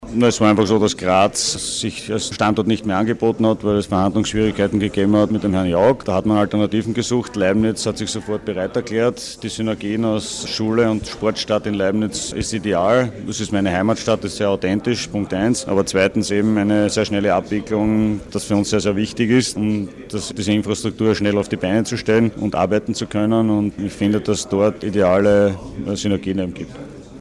Statements
Fragen an Thomas Muster: